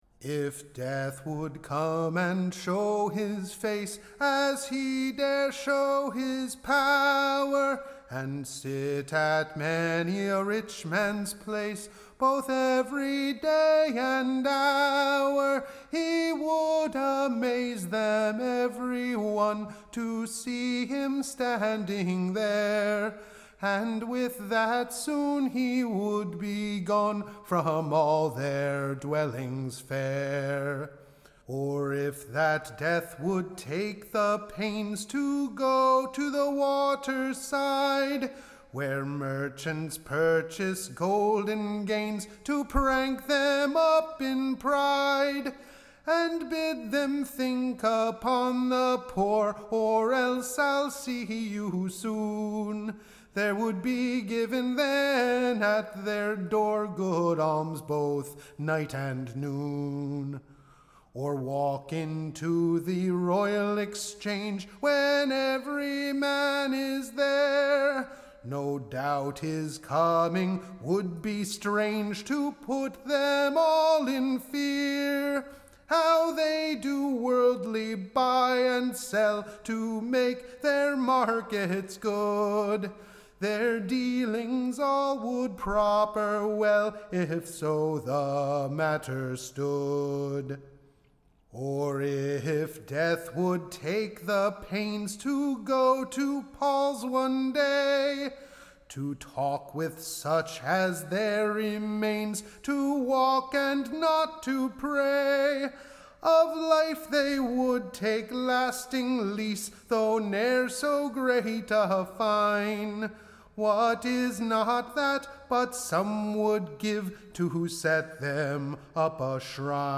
EBBA 30063 - UCSB English Broadside Ballad Archive
Tune Imprint To be sung to a pleasant new tune, cal[led] Oh no, no, no, not yet, or, the meddow brow.